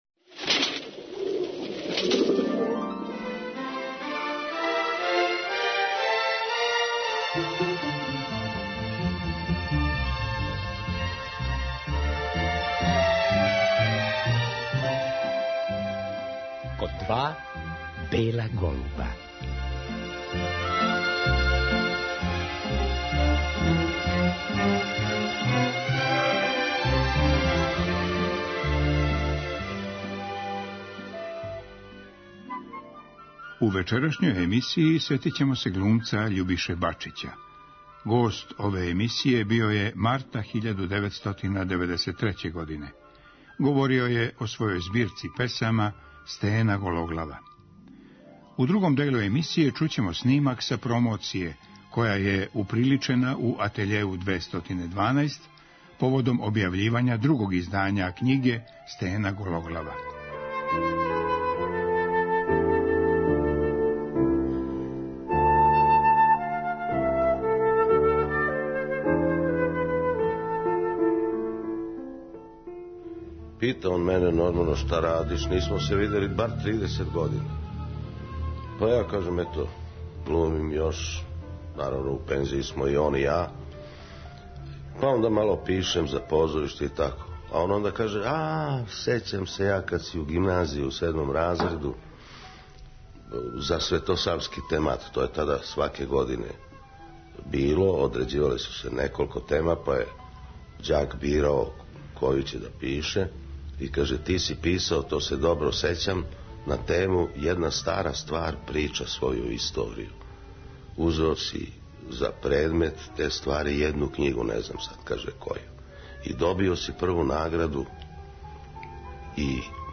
На годишњицу рођења Љубише Баје Бачића, позоришног глумца и песника слушаћемо разговор који је снимљен за ову емисију 1993. године.
Песме Баје Бачића говорили се његове колеге глумци. Снимак са тог догађаја чућемо у вечерашњој емисији.